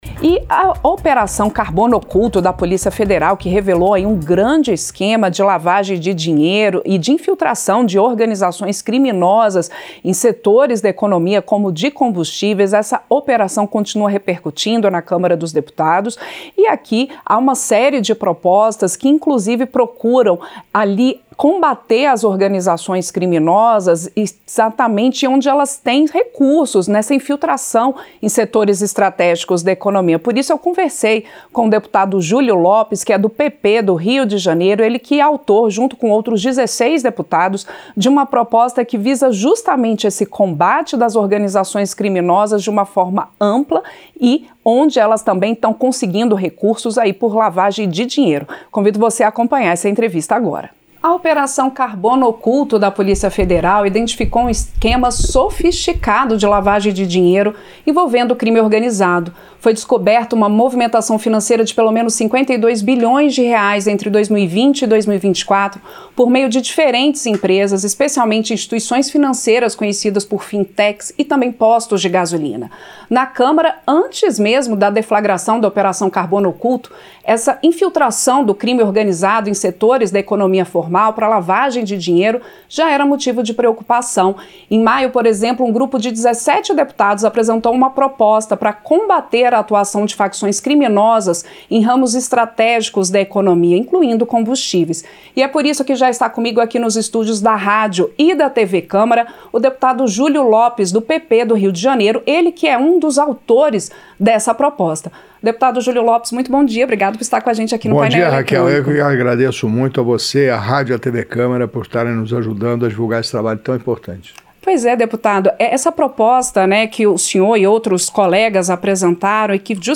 As investigações em curso pela Operação Carbono Oculto, da Polícia Federal, ainda vão mostrar uma extensão muito maior de infiltração do crime organizado em setores formais da economia, como de combustíveis. A avaliação foi feita pelo deputado Julio Lopes (PP-RJ) em entrevista ao Painel Eletrônico (4).
Entrevista - Dep. Júlio Lopes (PP-RJ)